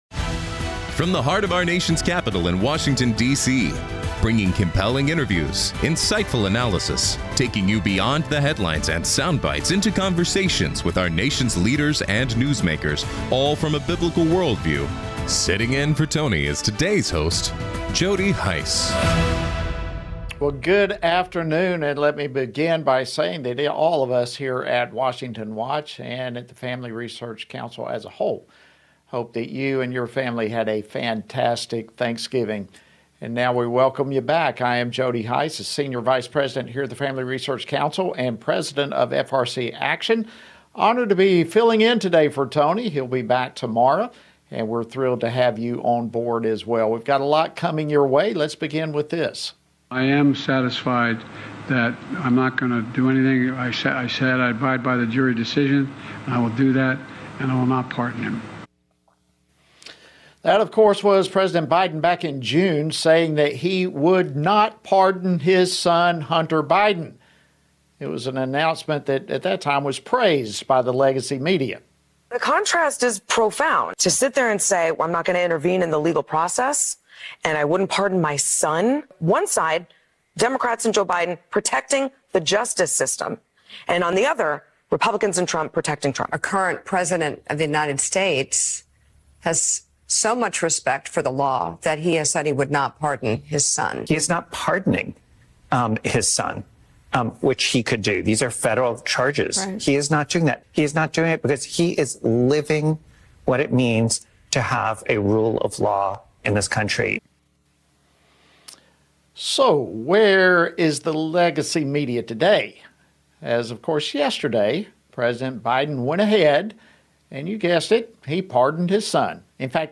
On today’s program, hosted by Jody Hice: Andy Biggs, U.S. Representative for the 5th District of Arizona, reacts to President Biden’s full pardon of his son Hunter and previews what Congress will be tackling when they return to DC.